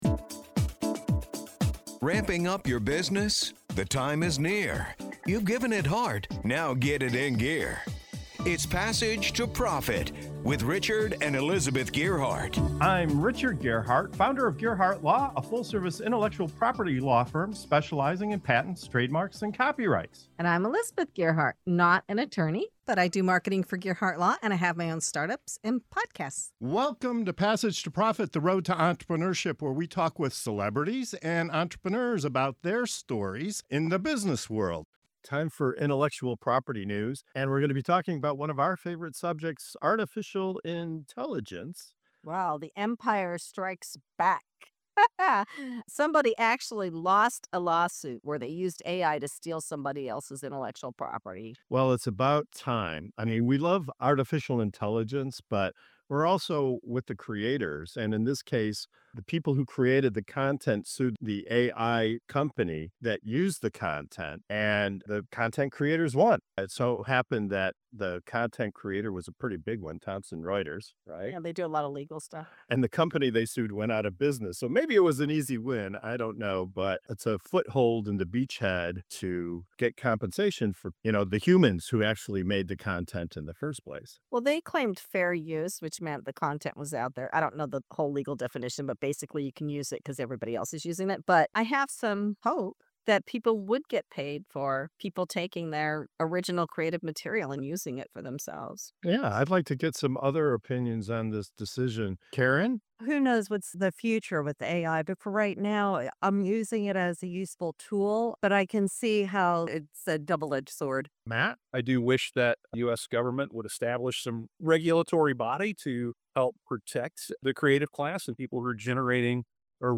In this segment of "Intellectual Property News" on Passage to Profit Show, we dive into a major lawsuit where content creators took on an AI company—and won. From fair use claims to the future of copyright law, our panel debates what this means for innovators, artists, and the legal system.